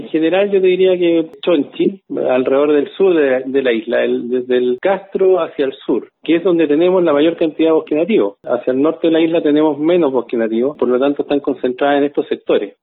Aichele, complementó que desde Castro al Sur, es donde más se corta el bosque nativo.